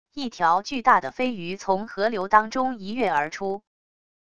一条巨大的飞鱼从河流当中一跃而出wav音频